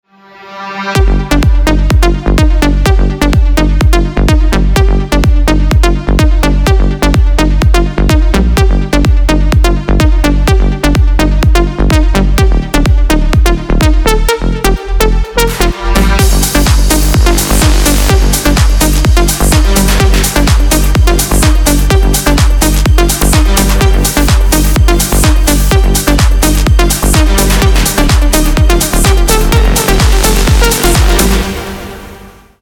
• Качество: 320, Stereo
ритмичные
громкие
Electronic
EDM
progressive house
динамичные
Стиль: progressive house